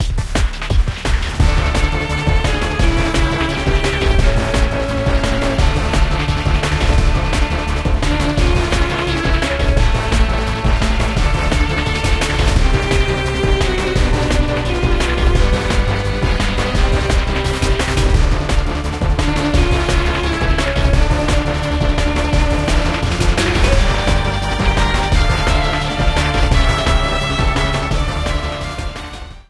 Ripped from the game
Fair use music sample